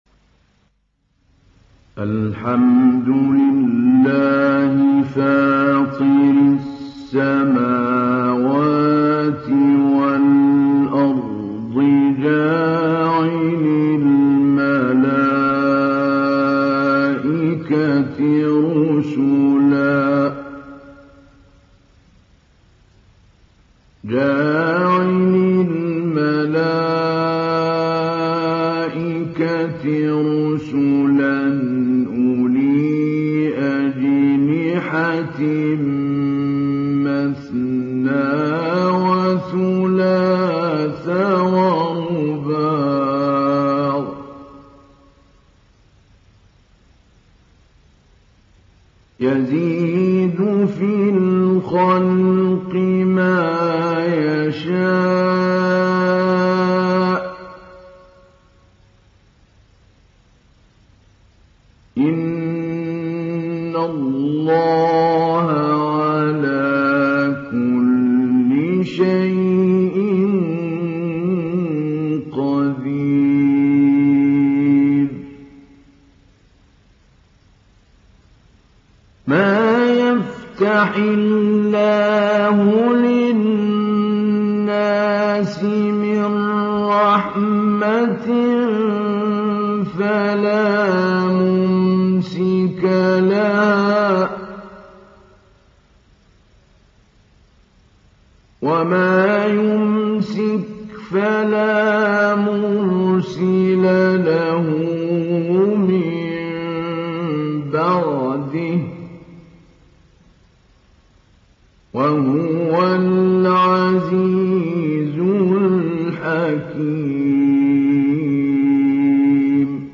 İndir Fatır Suresi Mahmoud Ali Albanna Mujawwad